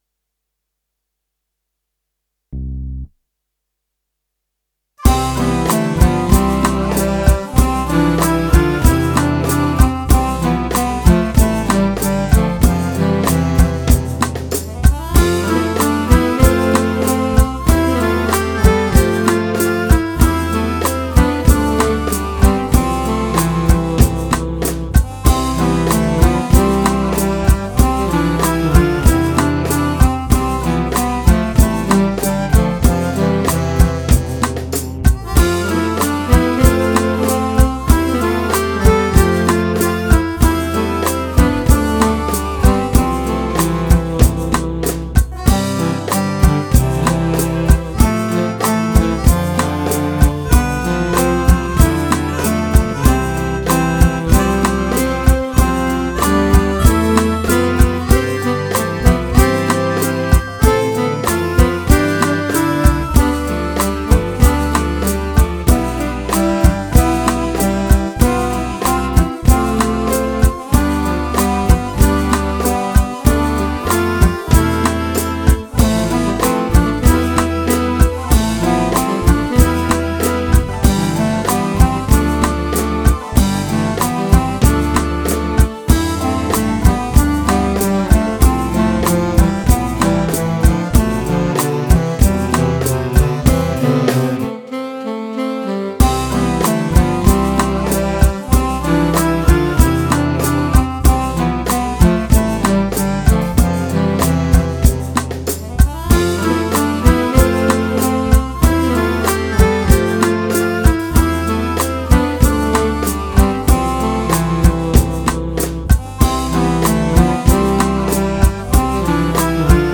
مقطوعة موسيقية